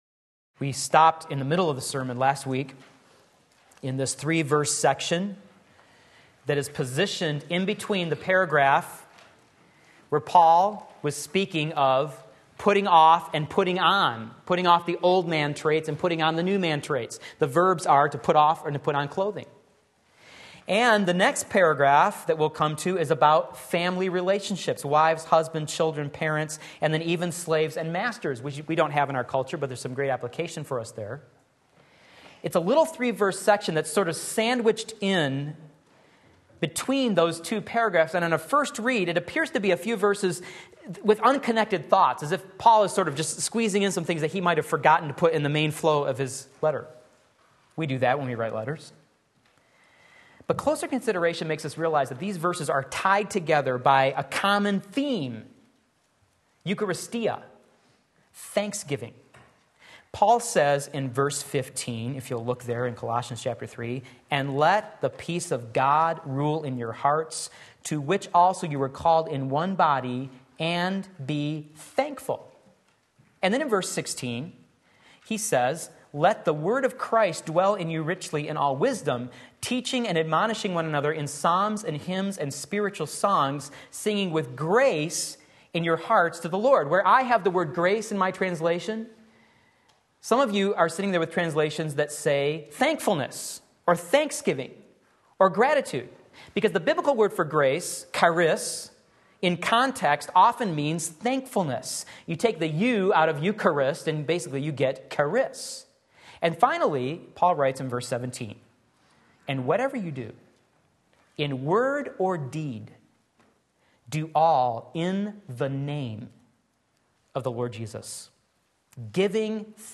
Sermon Link
Part 2 Colossians 3:15-17 Sunday Morning Service